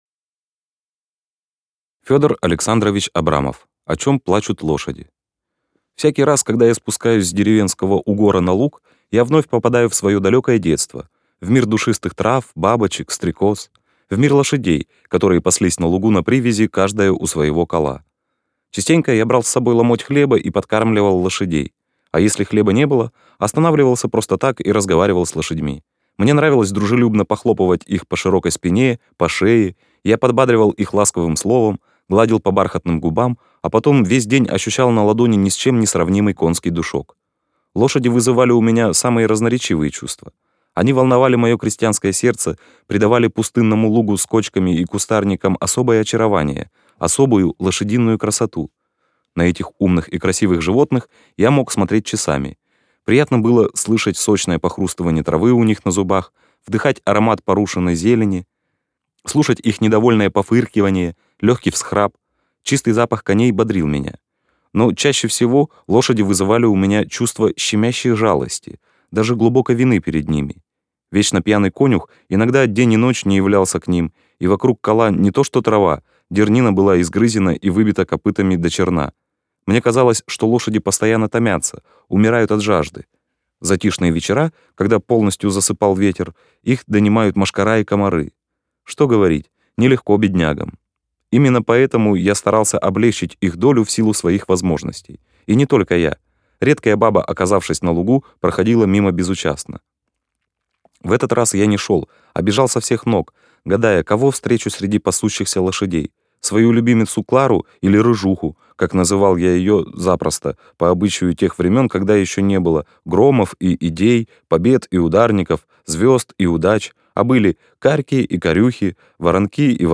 О чем плачут лошади - аудио рассказ Абрамова - слушать онлайн